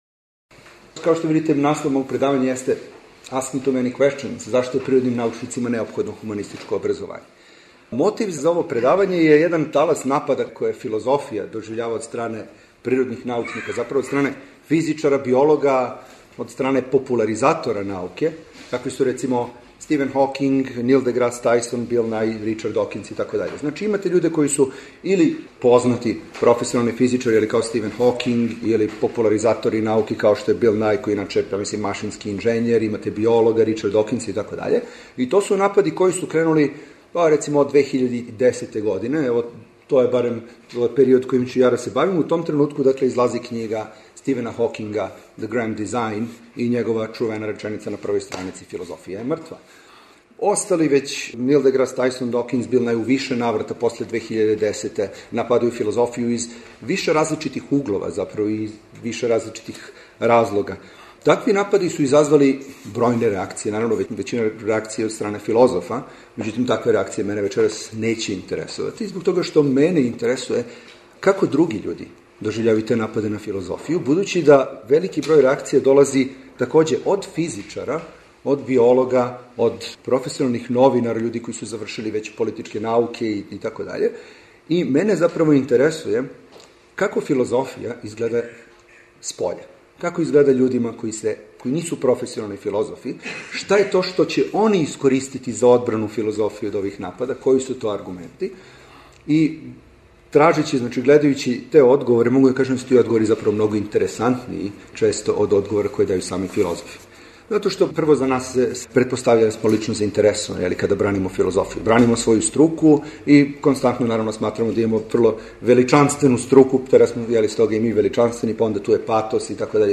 Предавања
преузми : 15.73 MB Радијска предавања, Дијалози Autor: Трећи програм Из Студија 6 директно преносимо јавна радијска предавања.